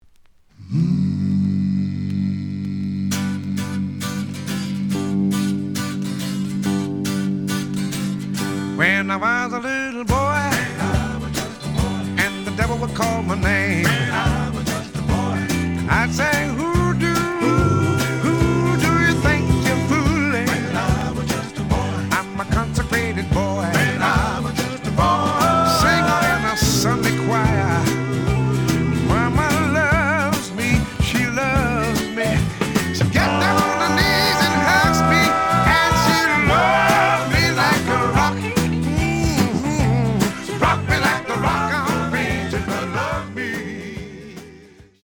The audio sample is recorded from the actual item.
●Genre: Gospel